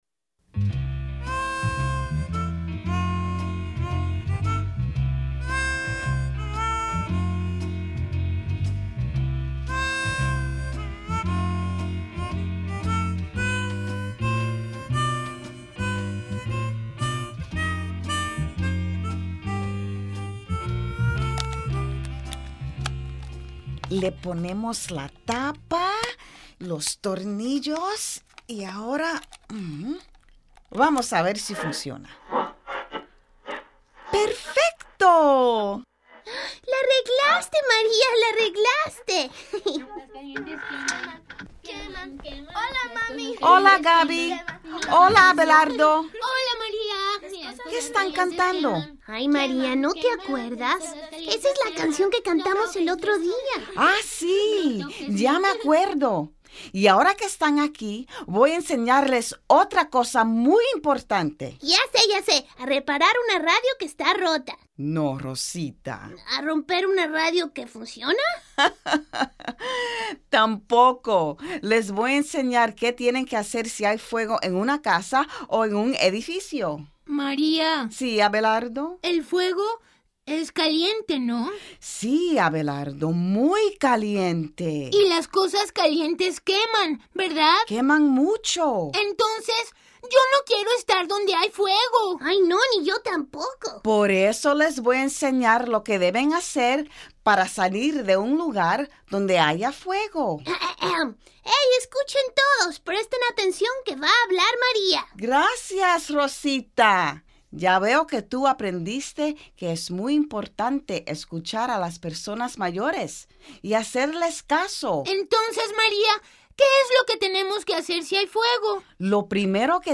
Songs and stories